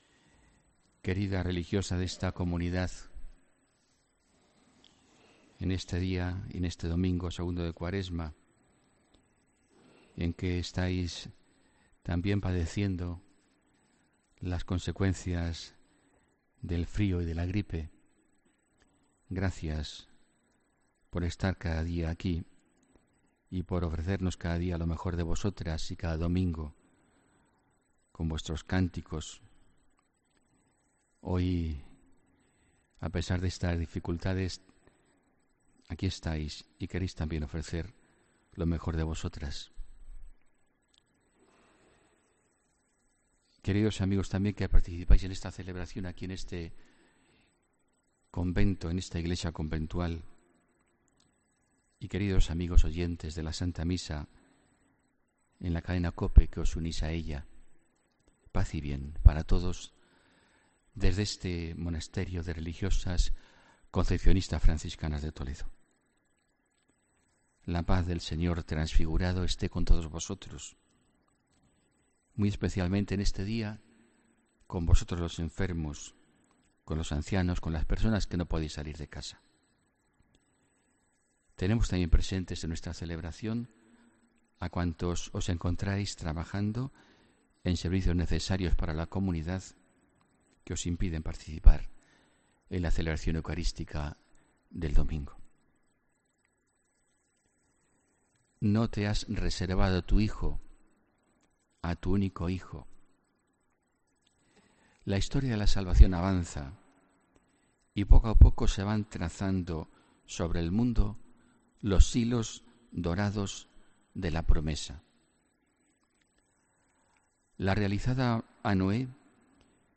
HOMILÍA 25 FEBRERO 2018